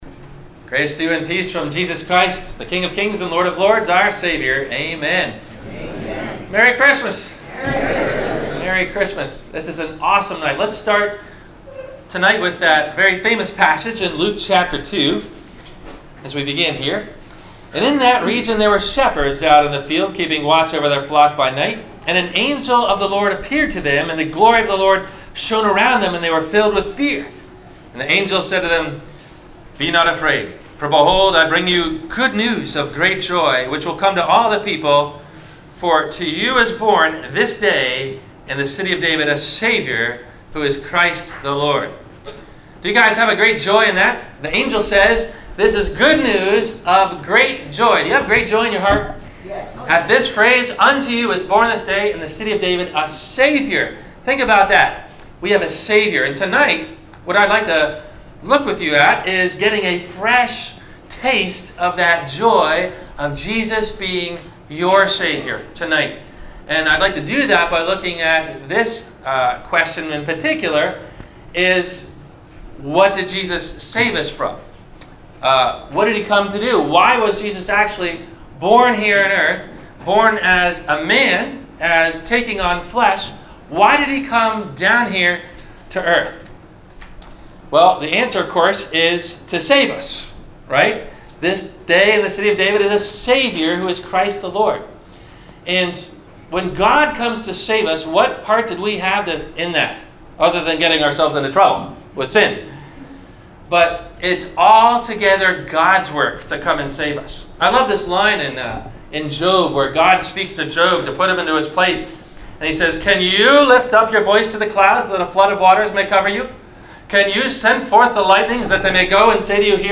How Deep is God's Love For Us? - Christmas Eve - Sermon - December 24 2017 - Christ Lutheran Cape Canaveral